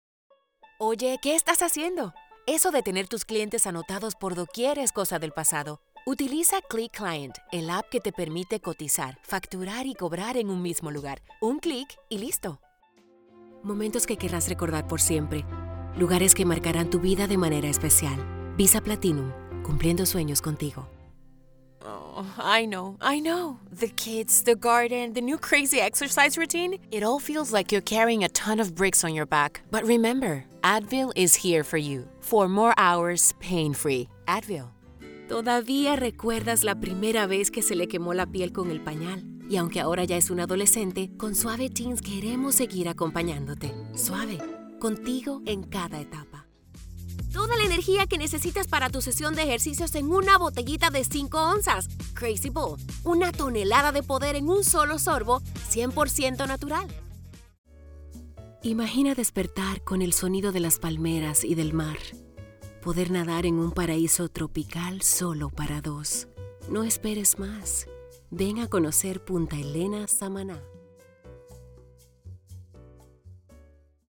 Commercial Demo
Professional home Studio
AT875R MIC
ConversationalConfidentWarmTrustworthyFriendlyCorporateVersatileYoungReliable